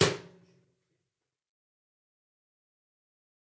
生活方式 " 微波炉门（加工品）